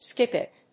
アクセントSkíp it. 《俗語》